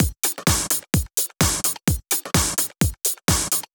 VMH1 Minimal Beats 03.wav